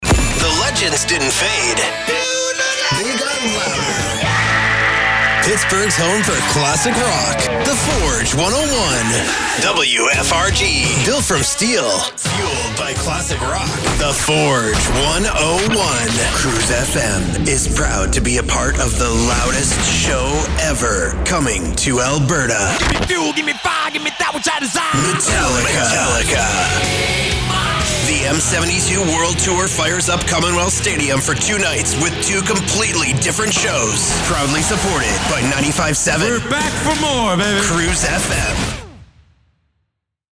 Male
My voice has "today's sound". It's young, fresh, cool, natural, conversational, relatable. I can do anything from laid back to hard sell and excited. I have a slight raspy and deep voice but can deliver upbeat young sounding copy with ease. I can do a cool, hip radio imaging voice from excited to smooth "mtv" type deliveries.
Radio Imaging Classic Rock